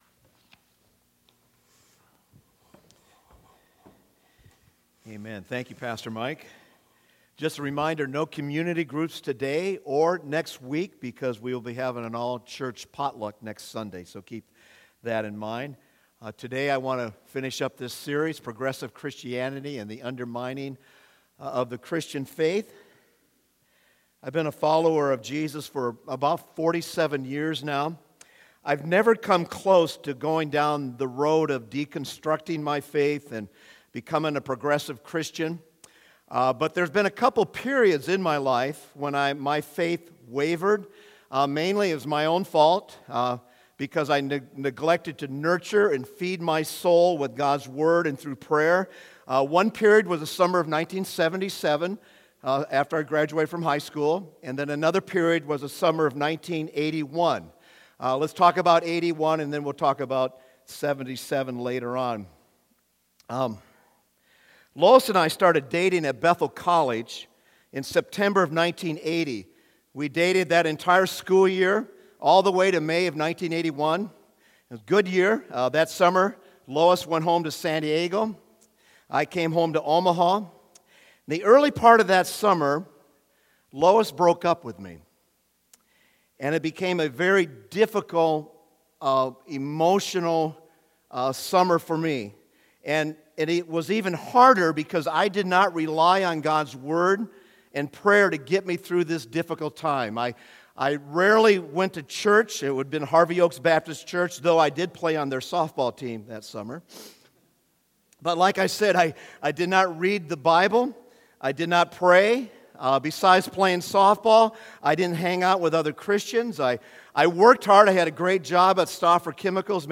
Sermons | Converge Church